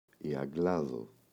αγγλάδω, η [a’ŋglaðo]